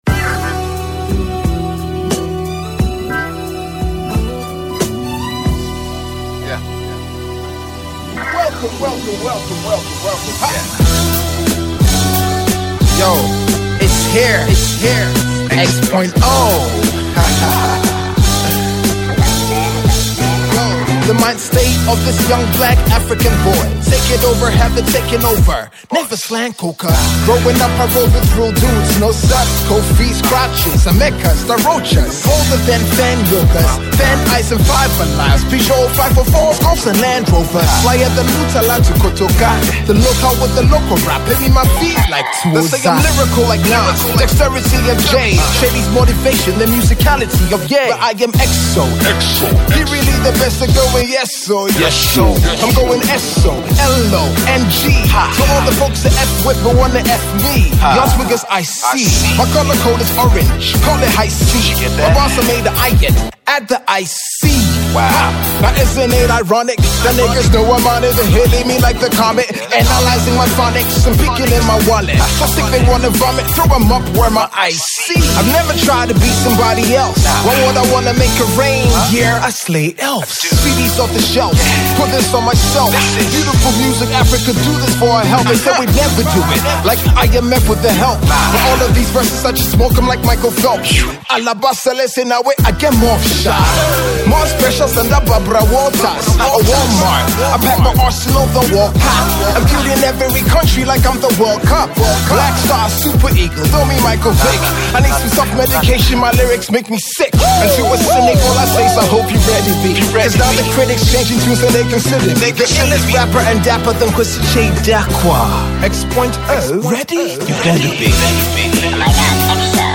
raps in excellent braggadocio form